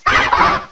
The cries from Chespin to Calyrex are now inserted as compressed cries
cramorant.aif